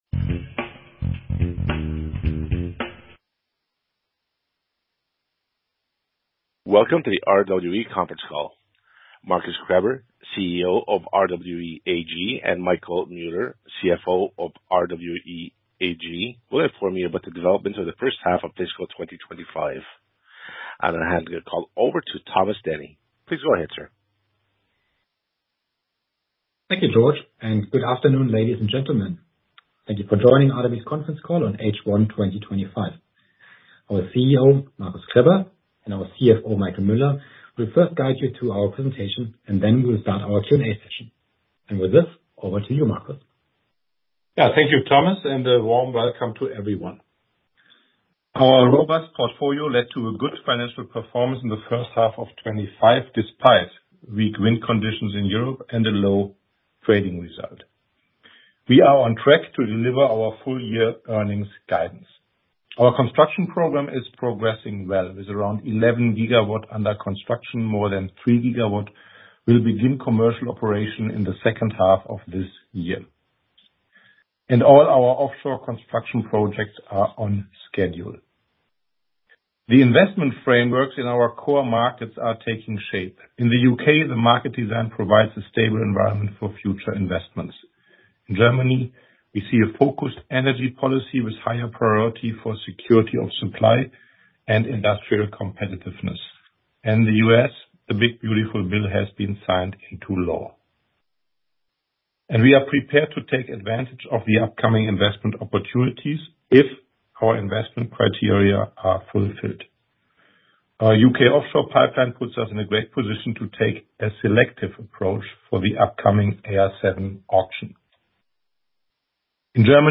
Investoren- und Analysten-Telefonkonferenz